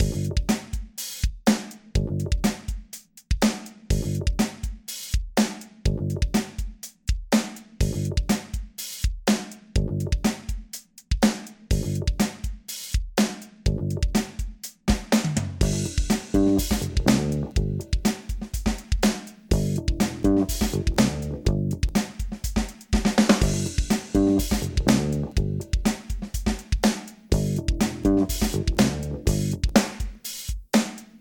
You can use the following audio loop for testing the filters:
funkbitsbassdrumsloop.mp3